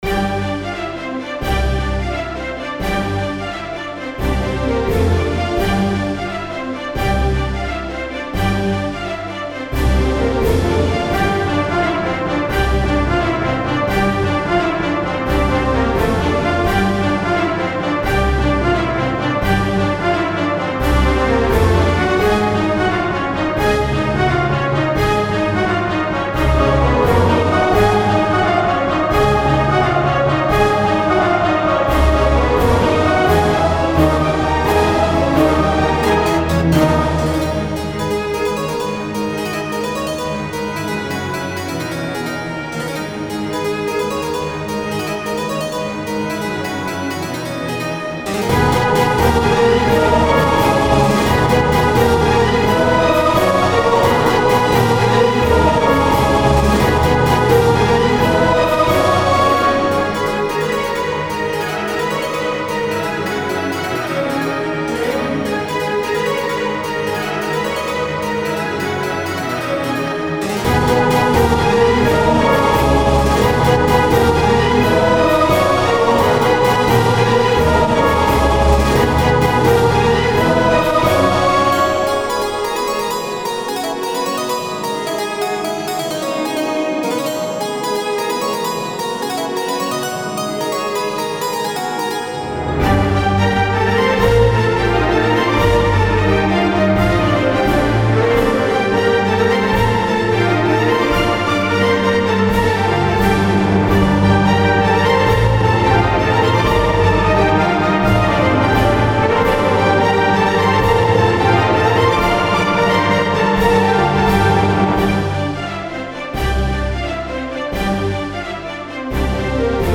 All I did was rewrite it for an orchestra :3
Game Music